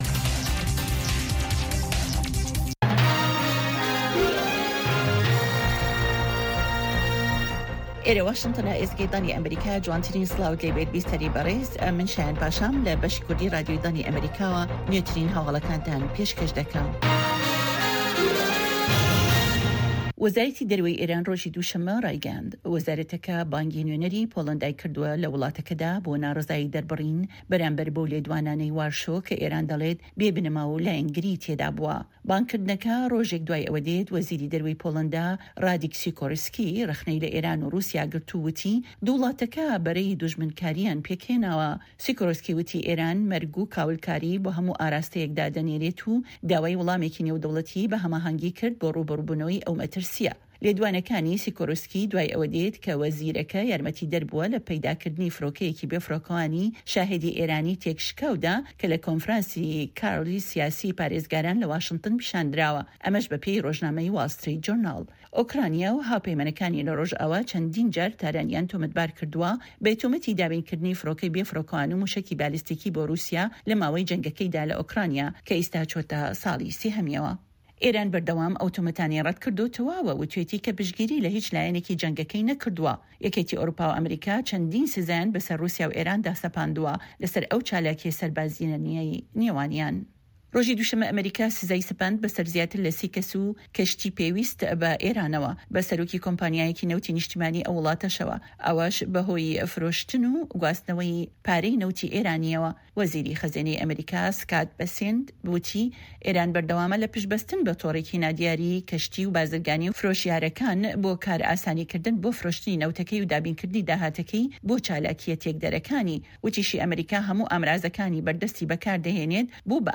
Nûçeyên Cîhanê 2